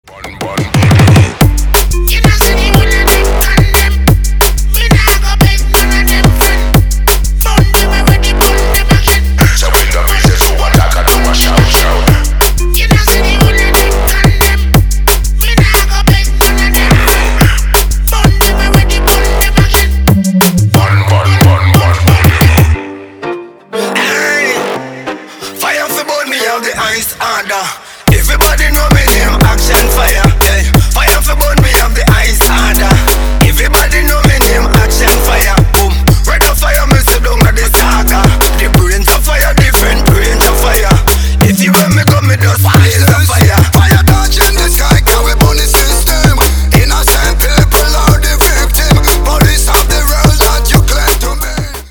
DubStep / DnB рингтоны